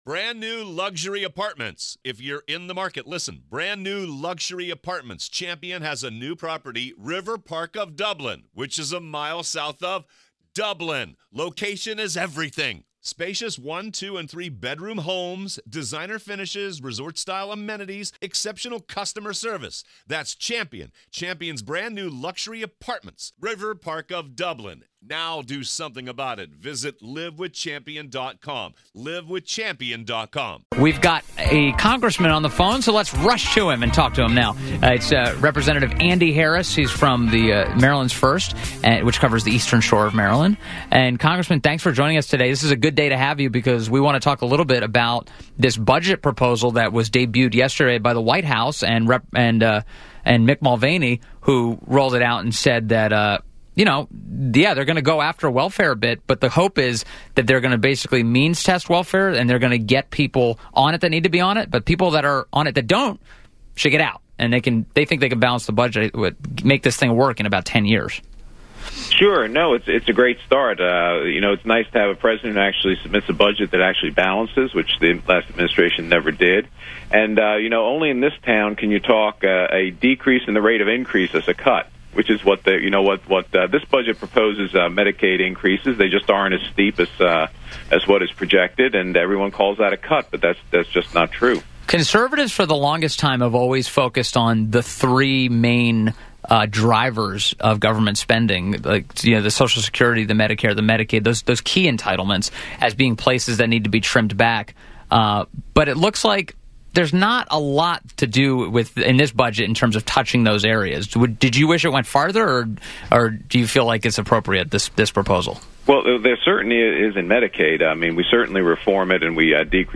WMAL interview Rep. Andy Harris; First District of MD. 052417